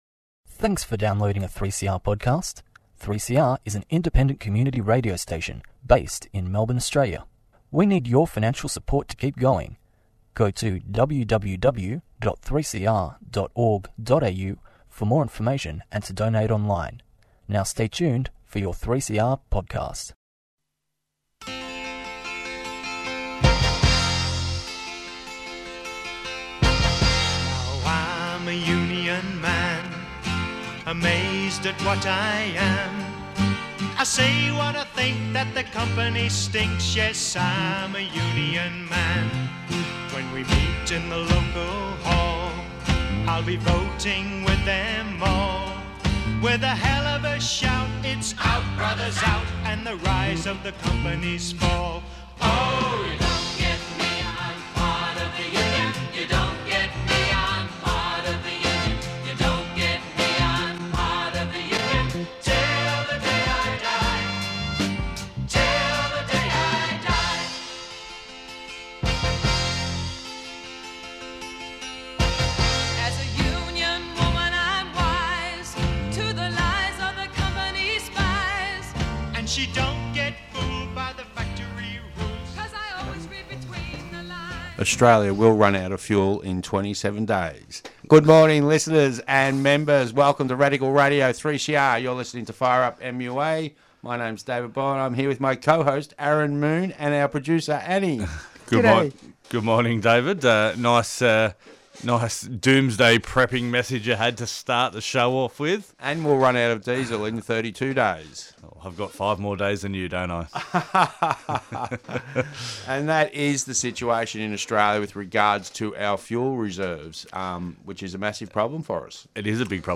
Fortnightly update from the MUA (Victoria). A discussion about peace being Union business followed by industrial reports from DPW, Linx, ANS, TTLine, Qube and Searoad.